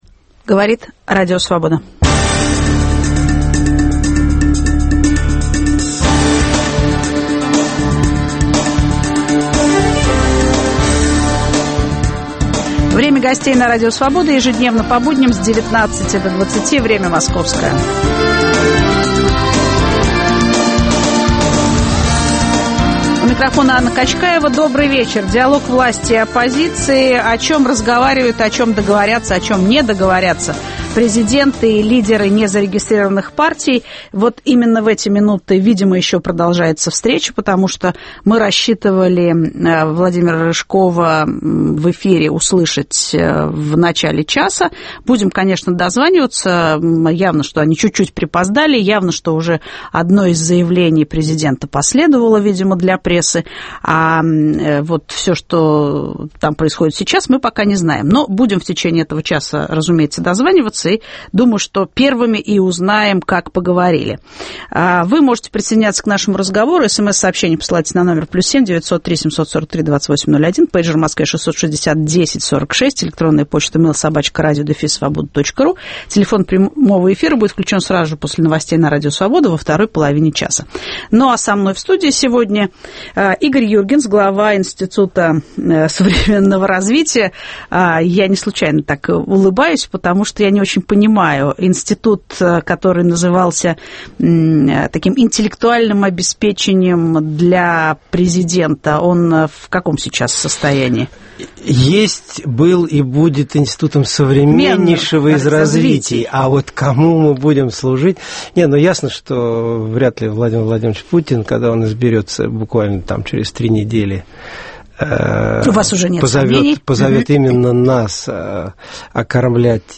Что обсуждали и о чем договорились президент и лидеры незарегистрированных партий? В прямом эфире после встречи с Дмитрием Медведевым политик Владимир Рыжков, в студии - глава ИНСОРа Игорь Юргенс.